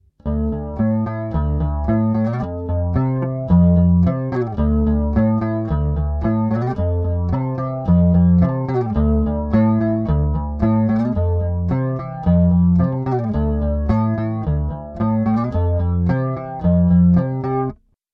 Flanger adds a jet-plane ‘swoosh’ for those all-important psychedelic moments.
Here a repetitive riff is enhanced with the addition of some flange (set to a low rate) to add some interest:
Electric Bass Flange
bt8_Flange.mp3